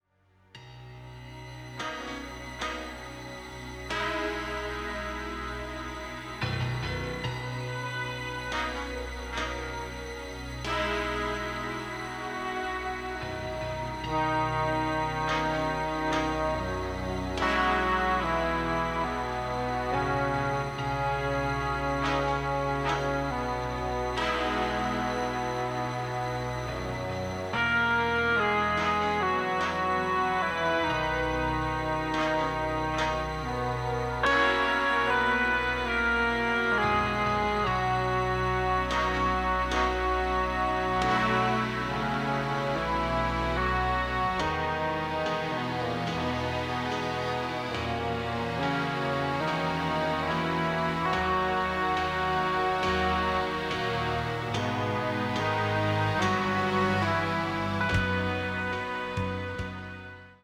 bizarre sci-fi score
electronic music